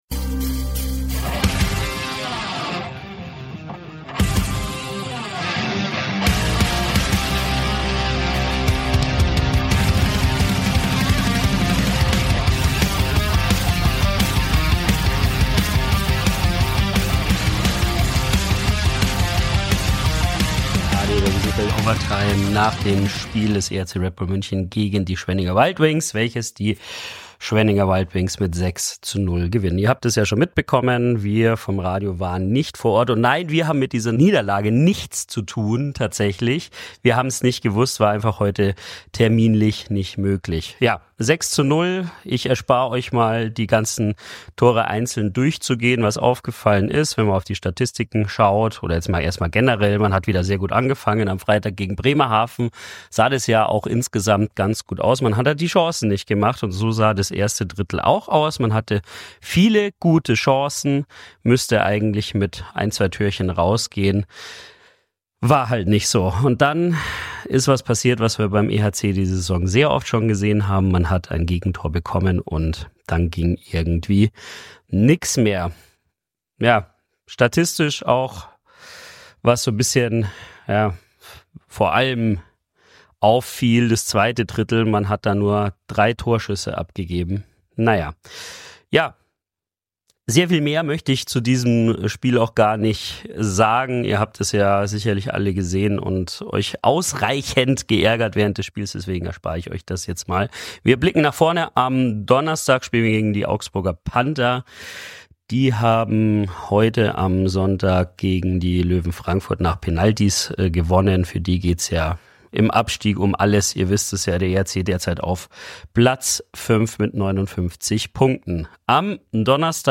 Spielzusammenfassung und Stimmen
Intro und Outro sind von der Band Viper Queen!